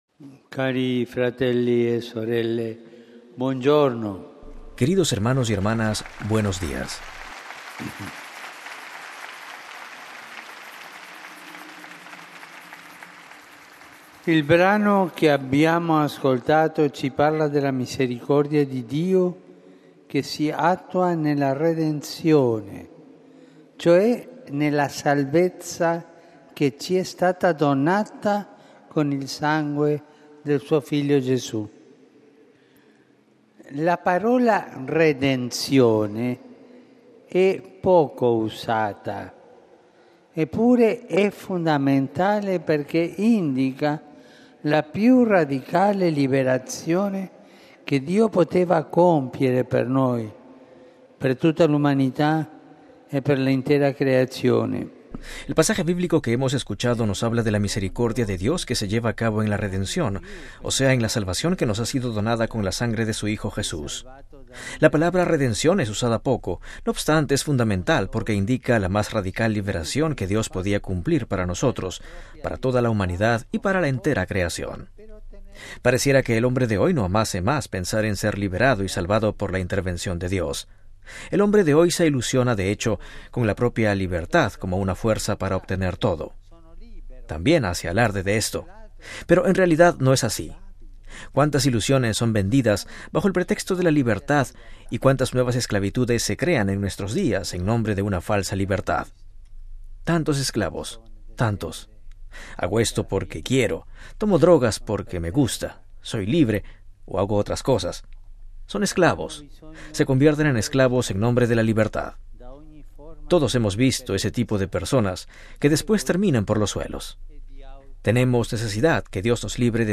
Audiencia Jubilar. Texto completo de la catequesis del Papa